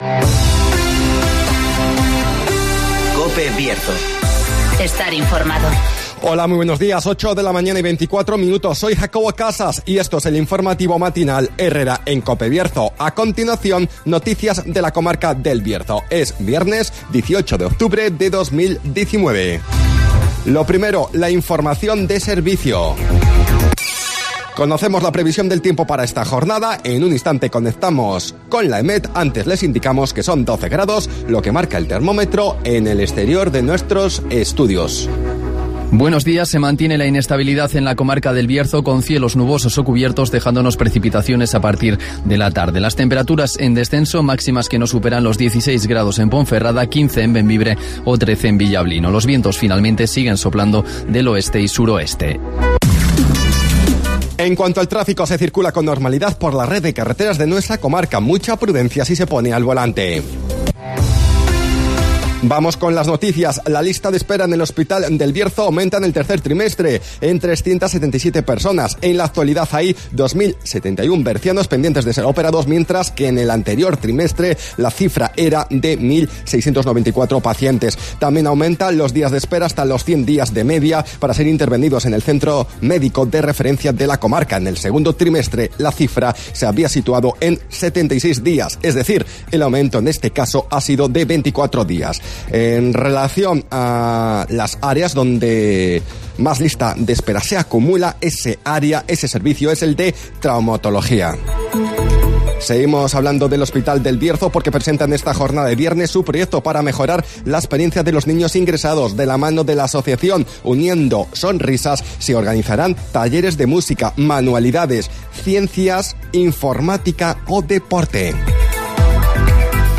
INFORMATIVOS BIERZO
Conocemos las noticias de las últimas horas de nuestra comarca, con las voces de los protagonistas